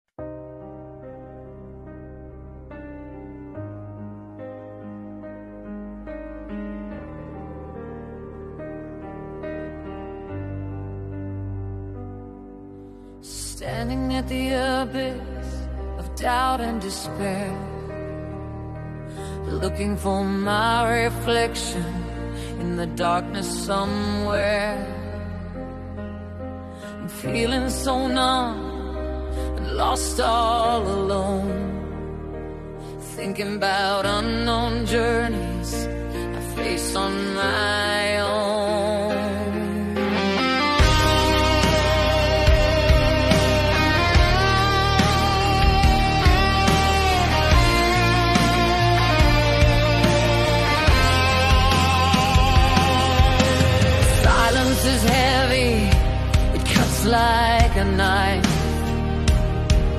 Nhạc EDM Remix